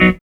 4505R GTRCHD.wav